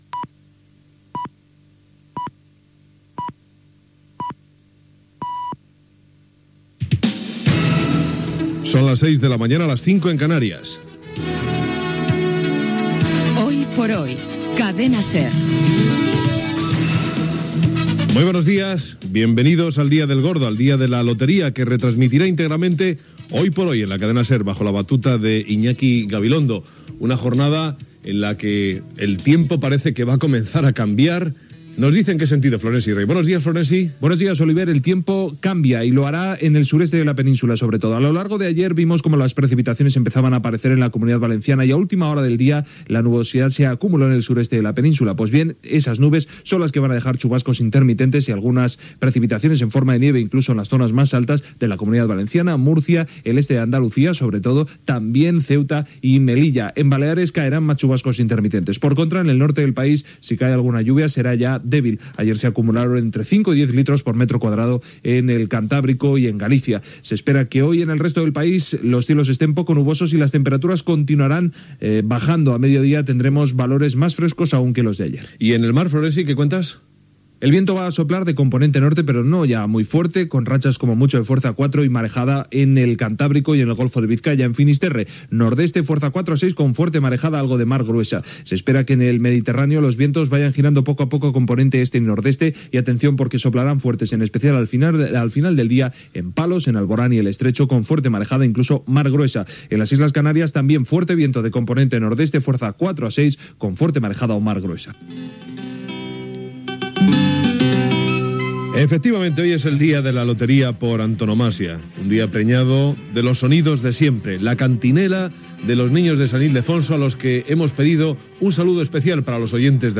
Senyals horaris, careta del programa, benvinguda al programa del dia de la rifa de Nadal, informació meteorològica. Salutació cantada dels nens de San Ildefonso abans del sorteig de Nadal. Dades sobre els nens que participaran al sorteig. Dispositiu de seguretat de Nadal del Ministeri d'Interior i altres notícies del dia.
Info-entreteniment